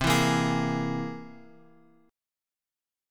C+7 chord {x 3 2 3 x 4} chord
C-Augmented 7th-C-x,3,2,3,x,4.m4a